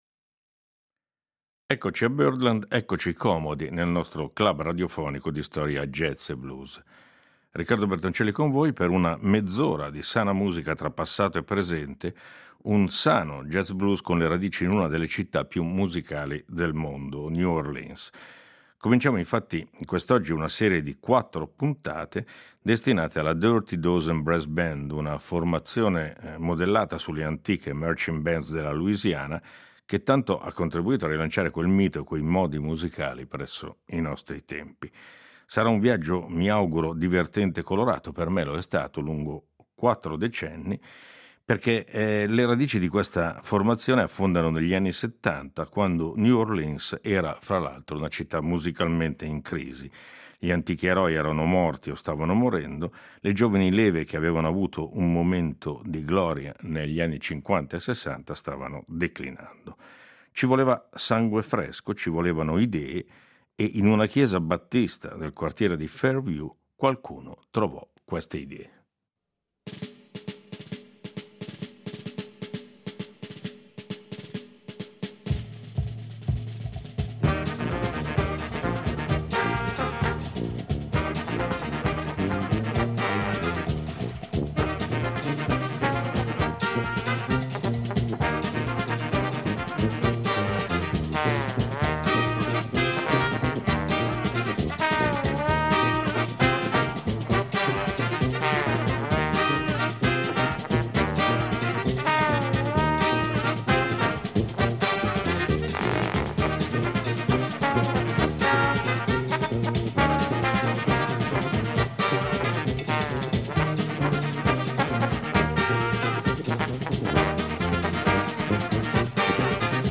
Con un sound che si richiama alle radici della musica della città (e a quello delle marchin’ band in particolare), al quale però si mescolano anche il funk e incandescenti ritmi di danza, il gruppo, anche grazie all’interessamento di un certo George Wein, il noto produttore – non fatica ad affermarsi in tutto il paese e pure in Europa.